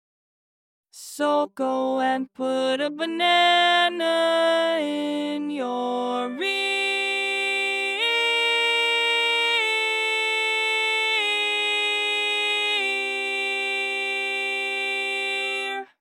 Key written in: C Major
Type: Female Barbershop (incl. SAI, HI, etc)